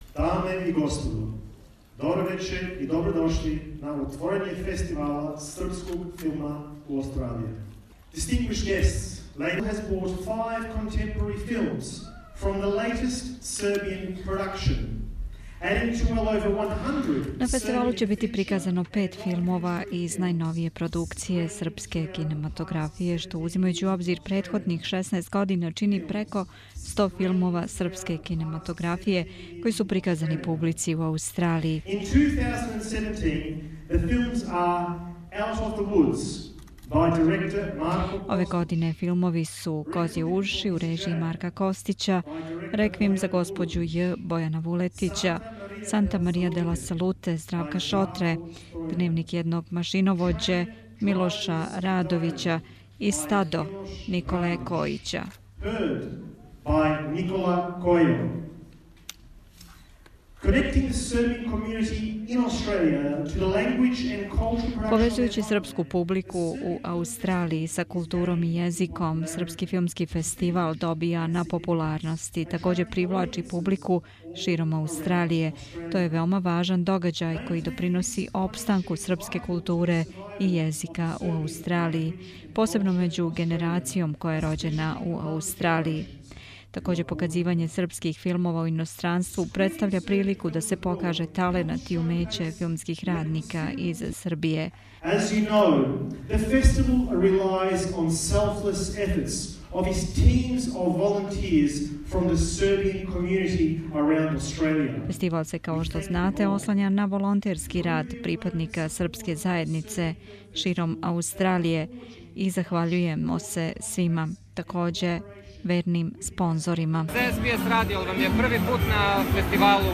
Синоћ је широм Аустралије званично отворен 17. Српски филмски фестивал. У Мелбурну фестивал је отоворен у биоскопу Хојтс, у Чадстон шопинг центру.
SFF Opening night Source